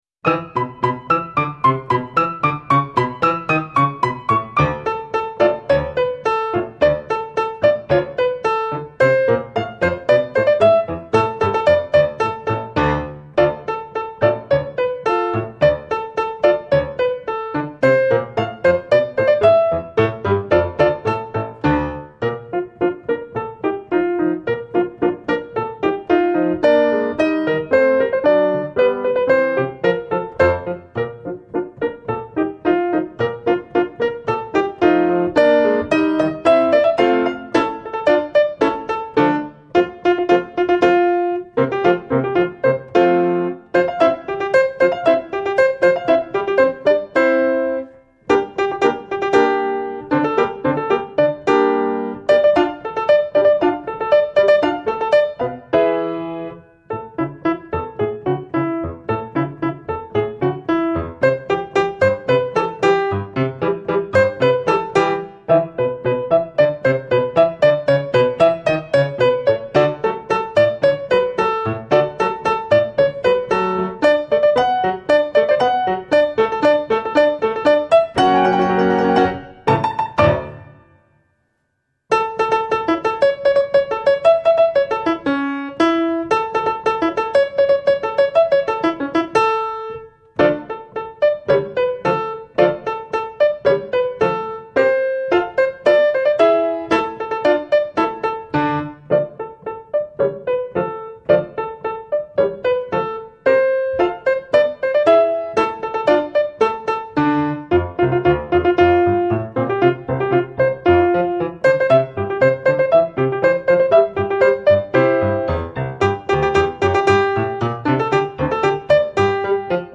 Il coro dei monelli – Cantata